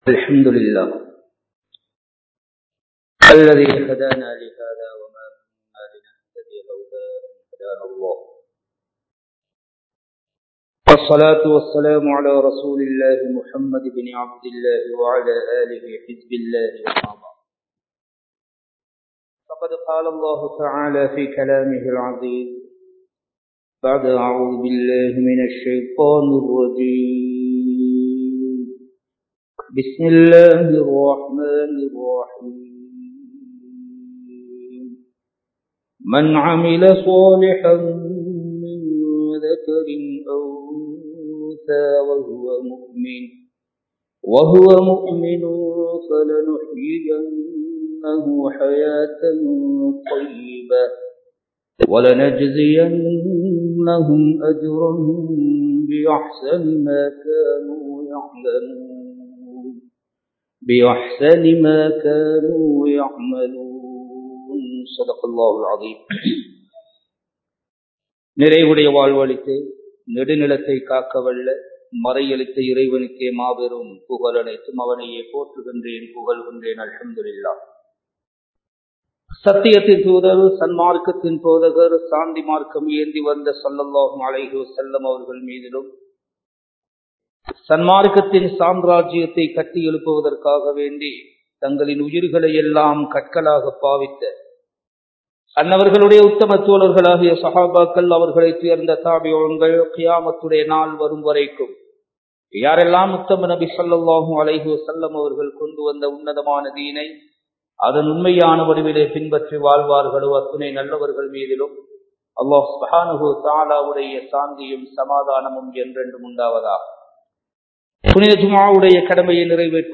சோதனைகளும் தீர்வுகளும் | Audio Bayans | All Ceylon Muslim Youth Community | Addalaichenai
Kandy, Kattukela Jumua Masjith